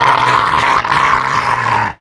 bdog_attack_11.ogg